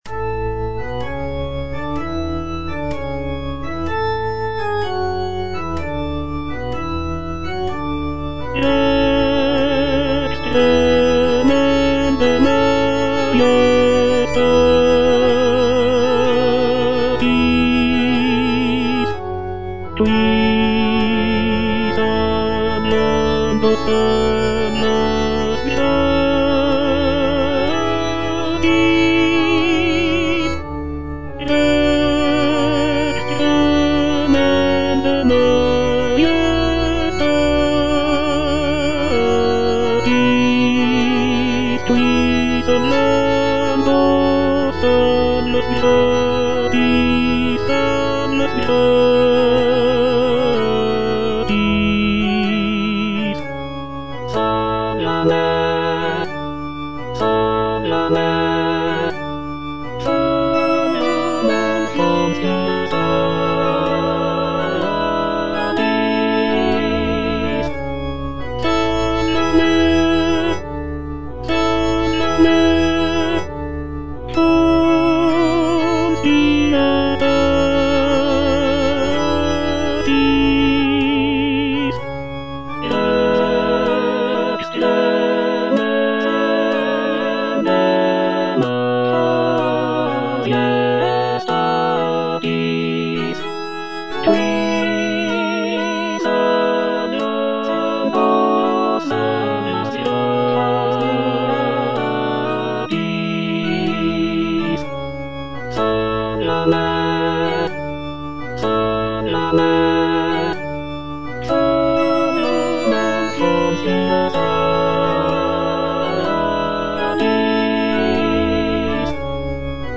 F. VON SUPPÈ - MISSA PRO DEFUNCTIS/REQUIEM Rex tremendae - Tenor (Voice with metronome) Ads stop: auto-stop